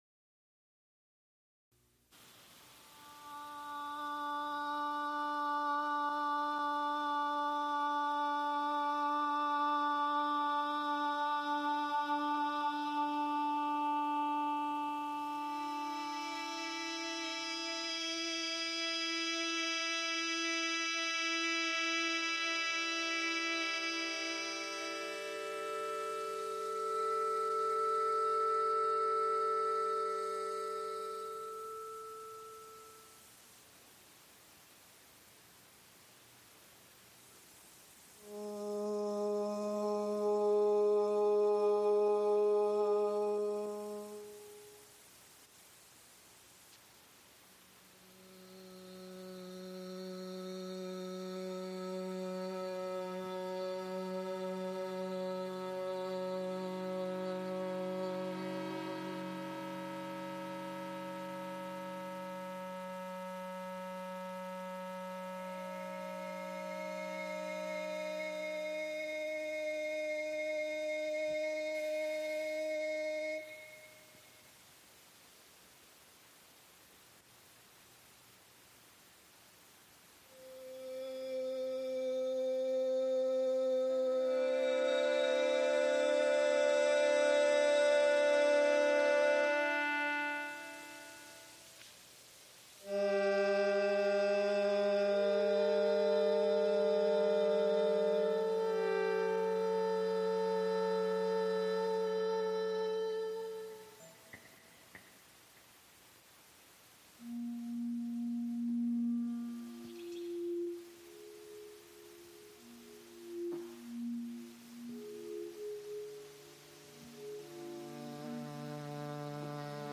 Electronic and computer music | Digital Pitt
New works in quadraphonic sound.
Frick Fine Arts Auditorium
Electronic music Computer music